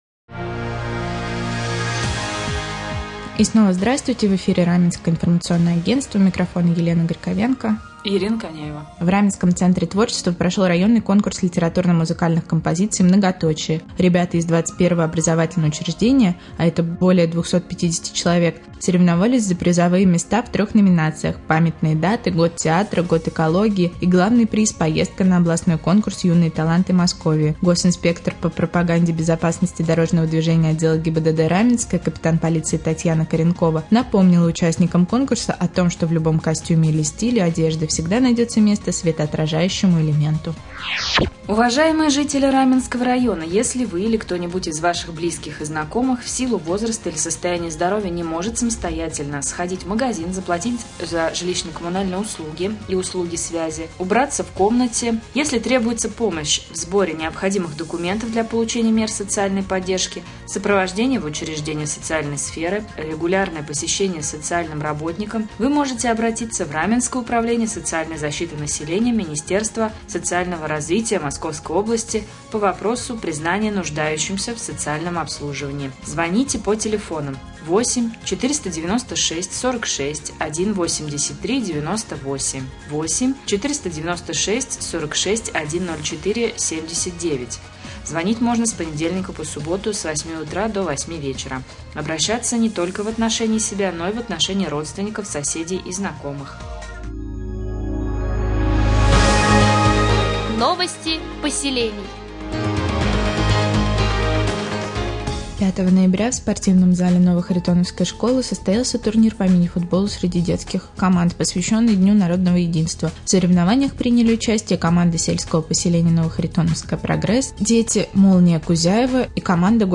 Куда обращаться для получения услуг соцобслуживания слушайте в новостном блоке Раменского радио